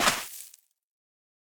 Minecraft Version Minecraft Version latest Latest Release | Latest Snapshot latest / assets / minecraft / sounds / block / suspicious_sand / step1.ogg Compare With Compare With Latest Release | Latest Snapshot
step1.ogg